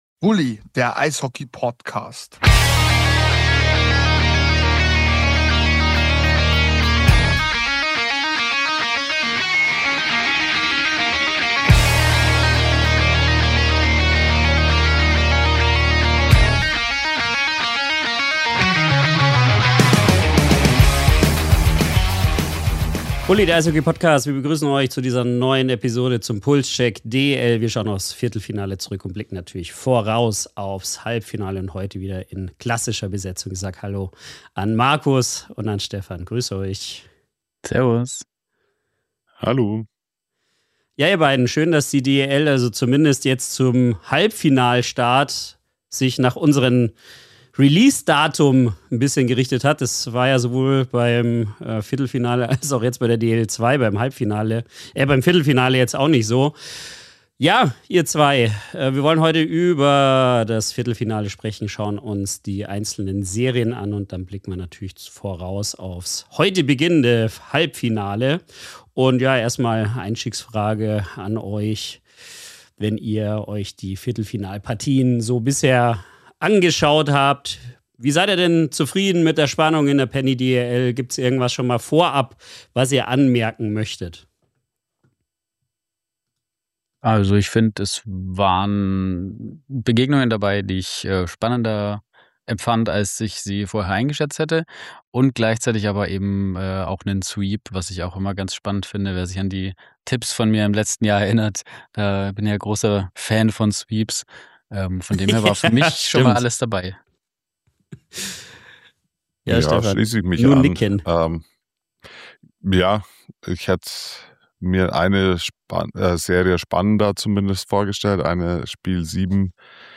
Experten